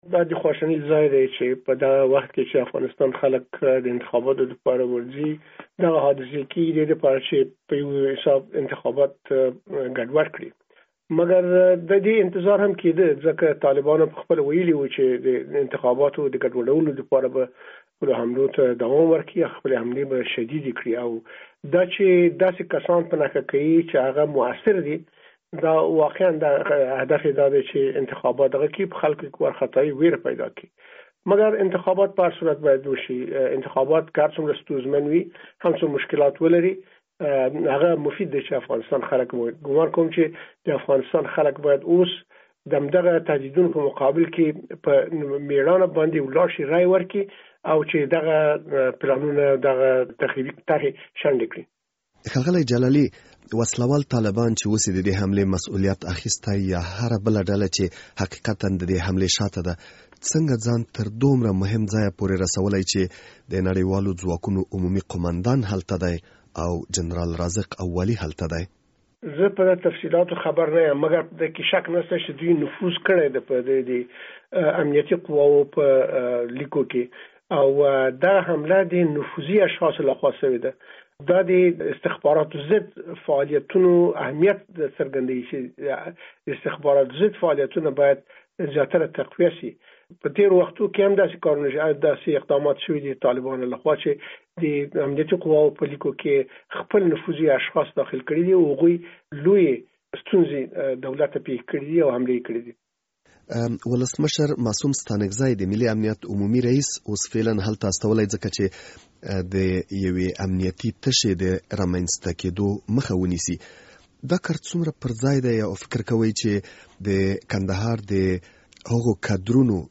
له ښاغلي جلالي سره مرکه